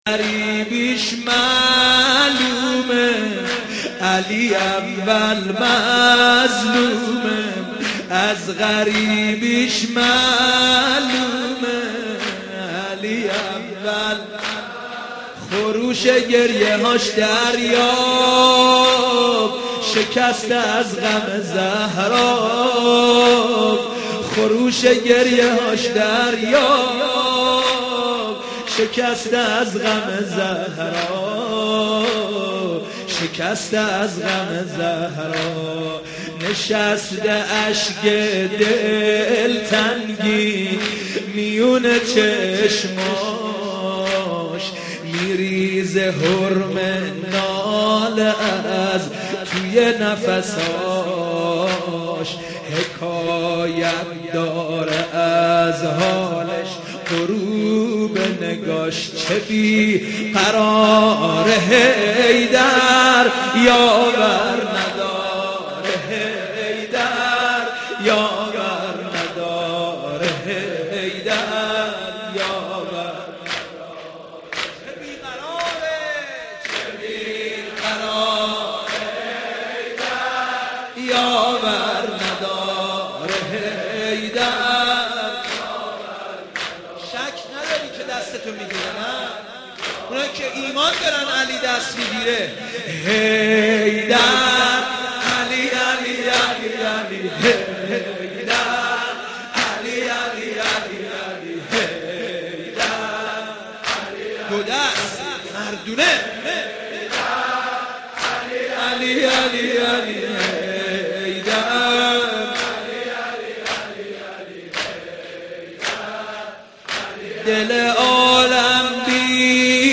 رمضان 89 - سینه زنی 1